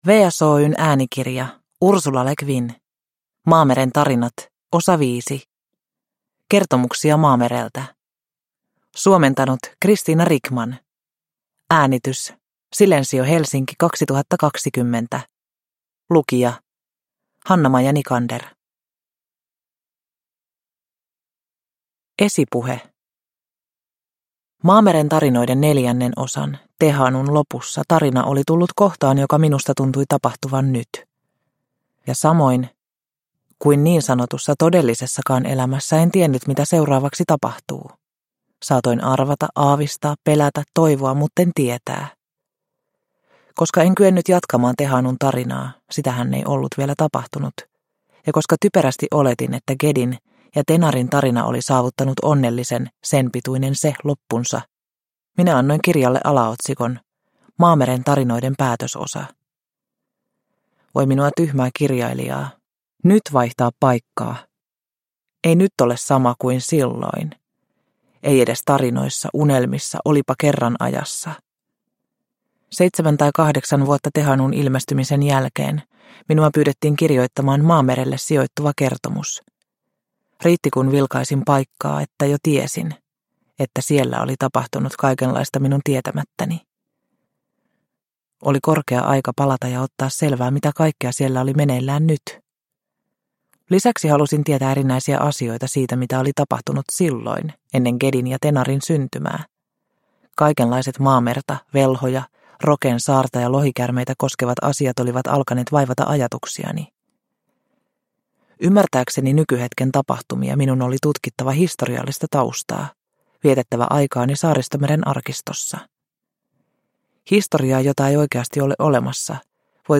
Kertomuksia Maamereltä – Ljudbok – Laddas ner